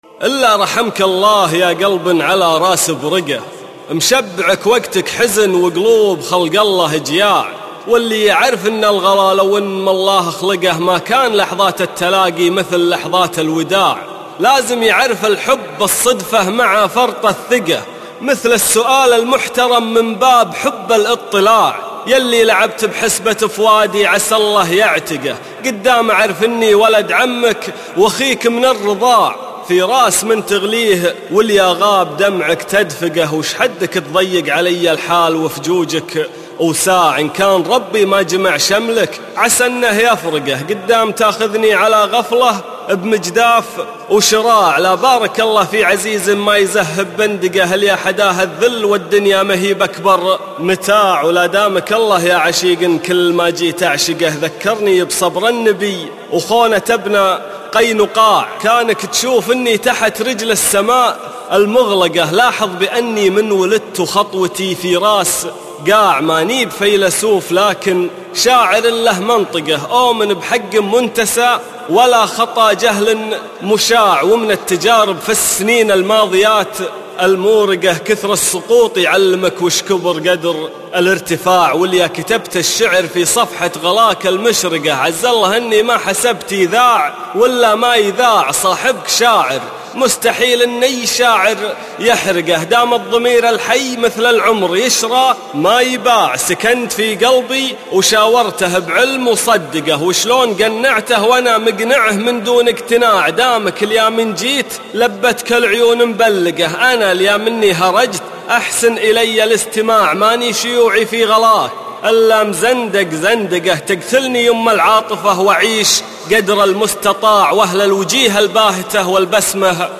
راس برقة - القاء حامد زيد